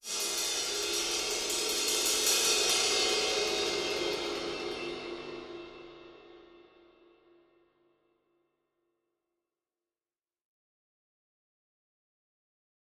Cymbal, Single, Crescendo, Type 1